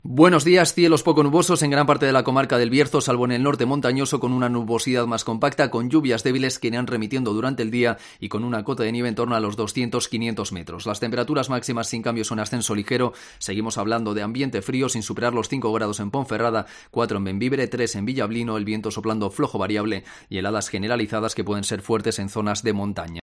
Previsión del tiempo